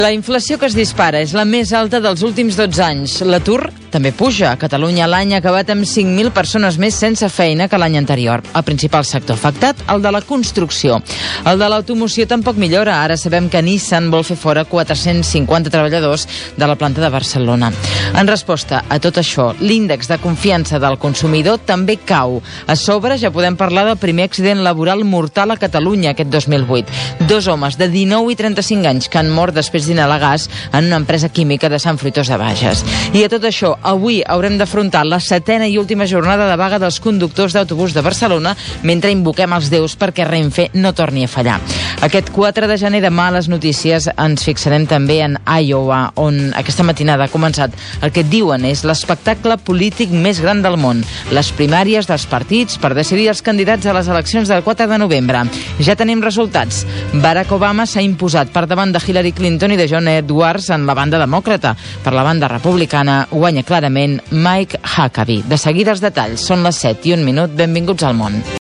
2aec1ab9e35f1033d4292c9d165c27bd1c6b267e.mp3 Títol RAC 1 Emissora RAC 1 Barcelona Cadena RAC Titularitat Privada nacional Nom programa El món a RAC 1 Descripció Sumari informatiu de les 7 del matí.